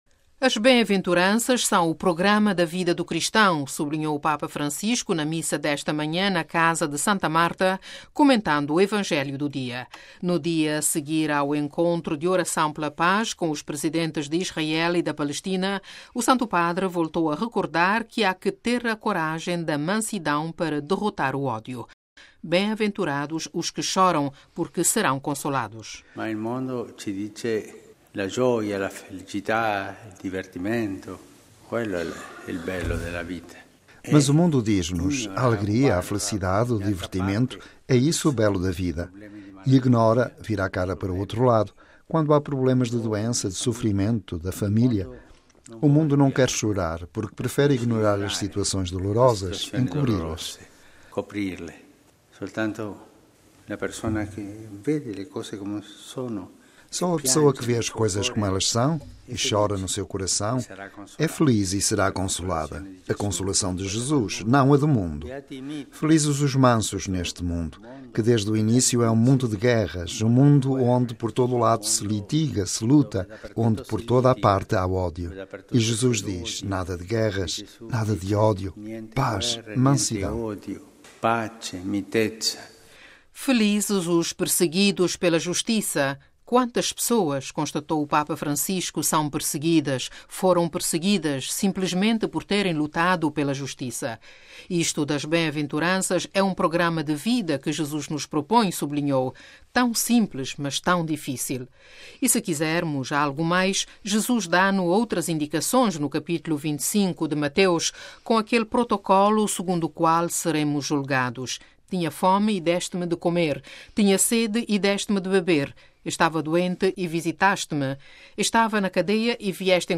As bem-aventuranças são o programa da vida do cristão: sublinhou o Papa Francisco na Missa desta manhã, na Casa de Santa Marta, comentando o Evangelho do dia.